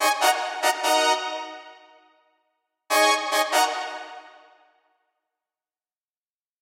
描述：用hardton live 8.斑马bas +延迟+压缩器制作的声音
标签： 回响贝斯 配音 空间 低音 雷鬼
声道立体声